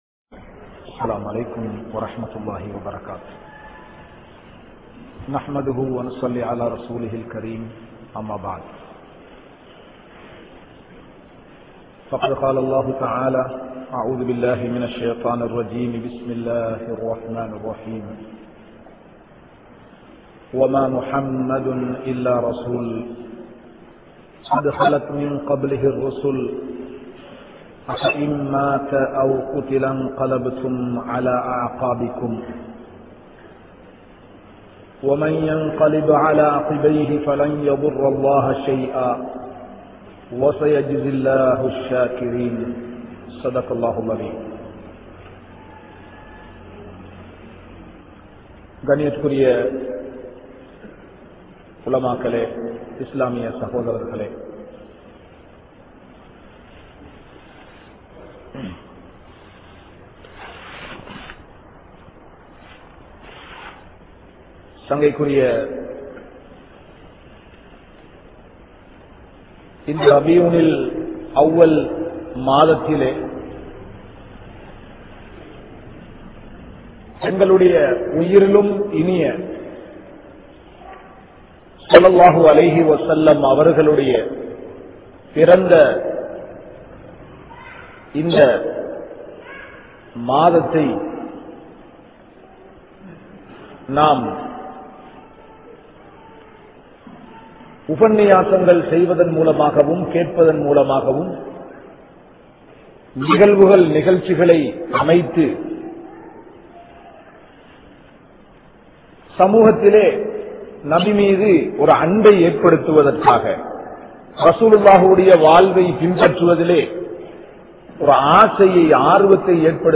Iruthi Nabien Iruthi Nimidam(இறுதி நபியின் இறுதி நிமிடம்) | Audio Bayans | All Ceylon Muslim Youth Community | Addalaichenai